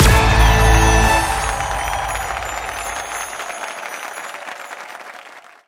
Win_Settle_Sound.mp3